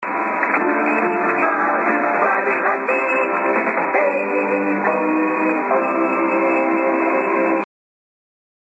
民放の開始と終了アナウンス集
録音環境　・受信場所：尾張旭市　・アンテナ：ＡＬＡ１５３０　・受信機：ＡＯＲ７０３０Ｐlus,ＪＲＣＮＲＤ−５４５